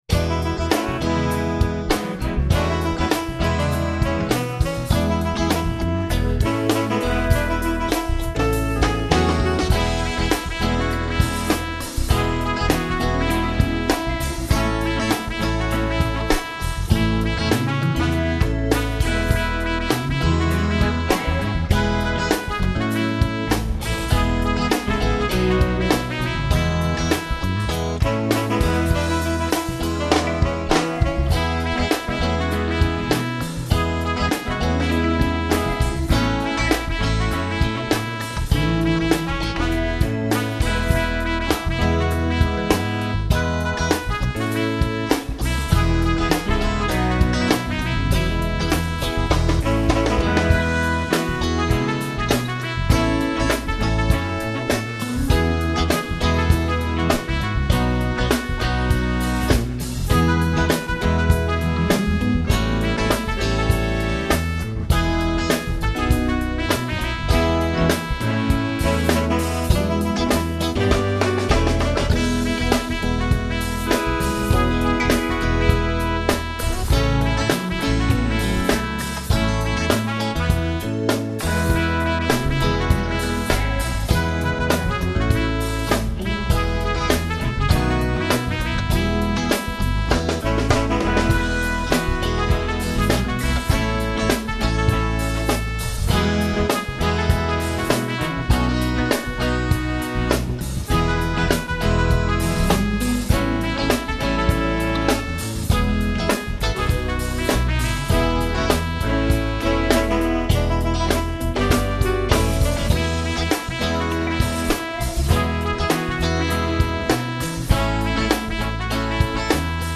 It’s meant to be Gospel rock but I just went for funk: